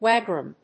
Wagram.mp3